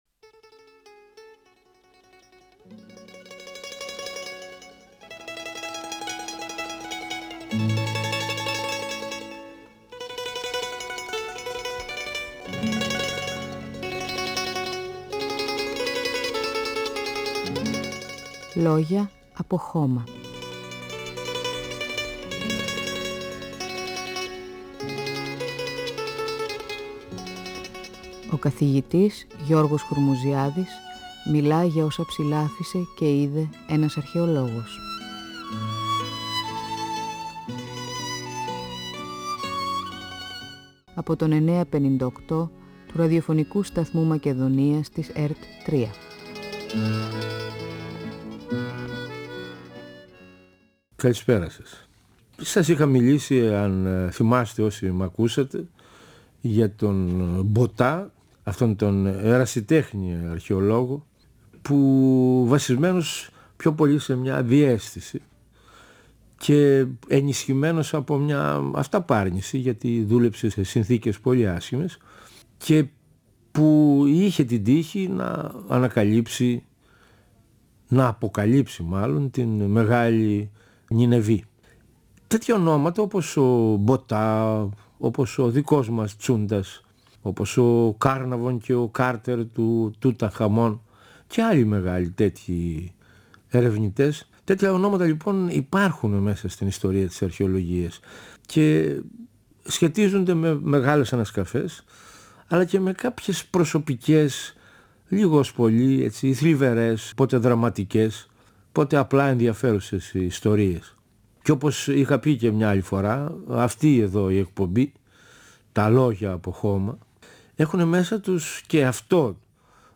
Διαβάζει αποσπάσματα από το ημερολόγιο του Ε.Σ.